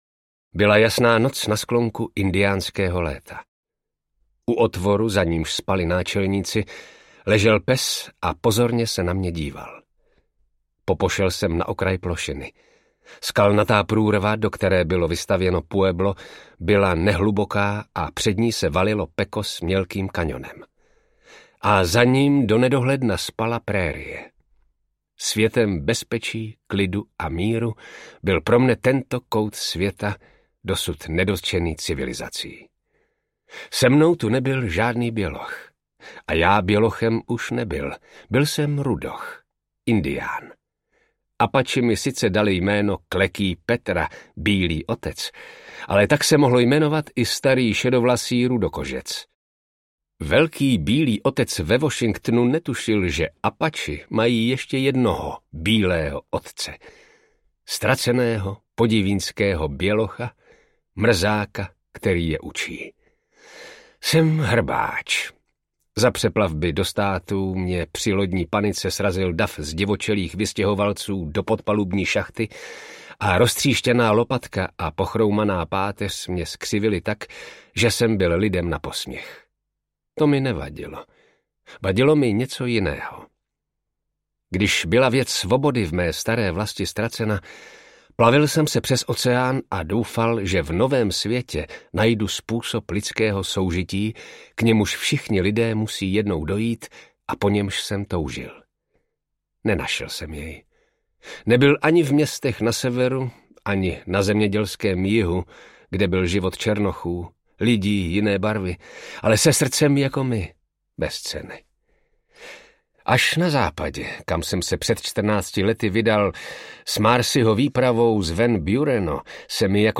Klekí Petra, bílý otec Apačů audiokniha
Ukázka z knihy